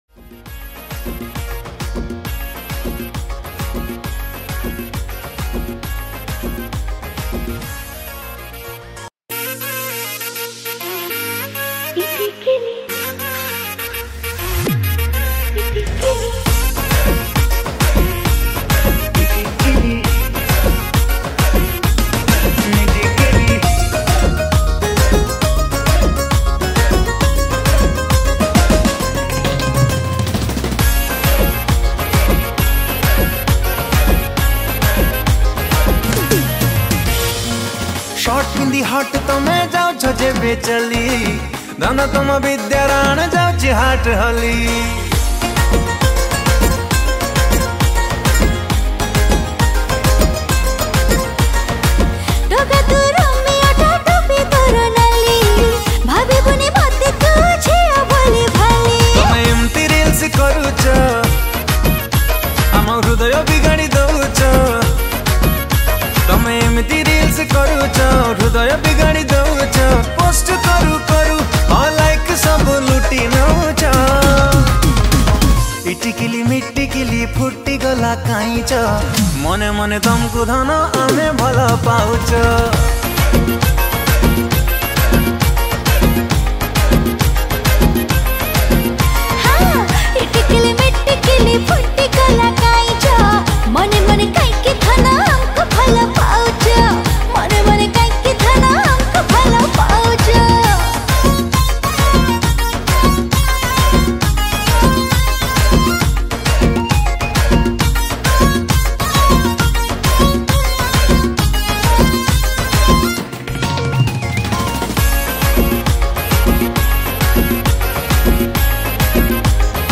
Keyboard Programing
Drums